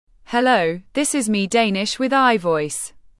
output_gtts.mp3